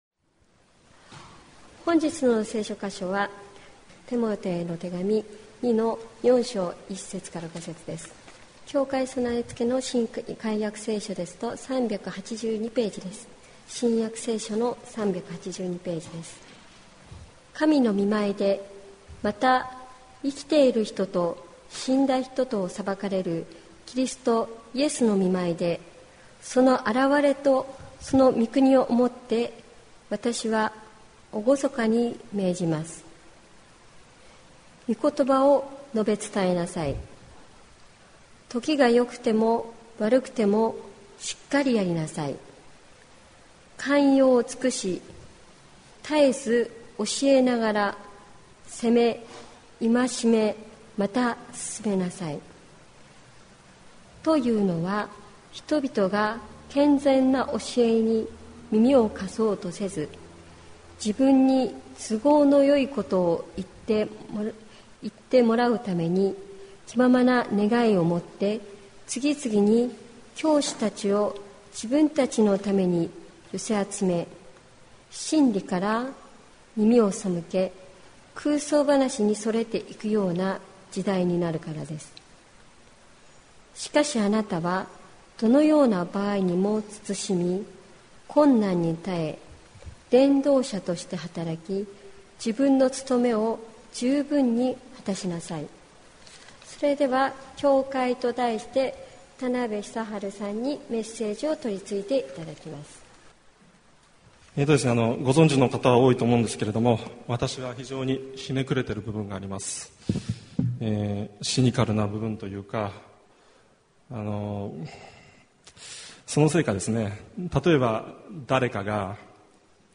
前回やった後、もうしばらくはやらないって思ったが、話の冒頭で説明したように、色々あって、やることになった。